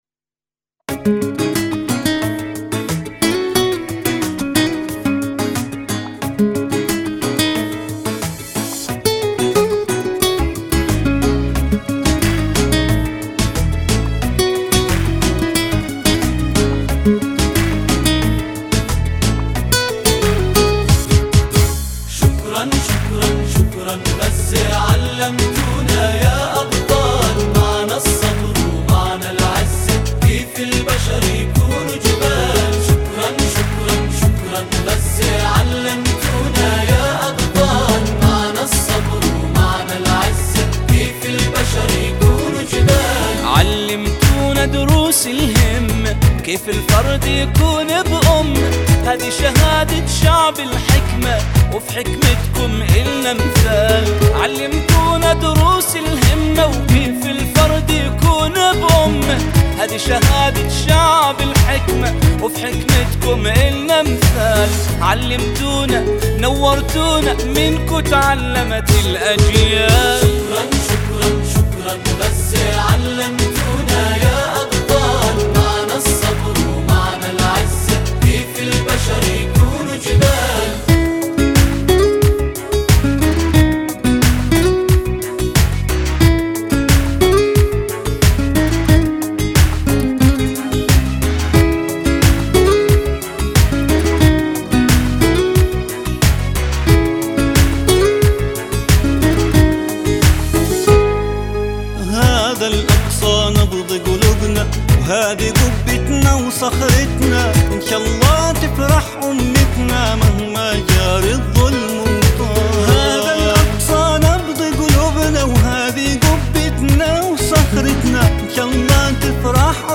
أناشيد فلسطينية... شكرا غزة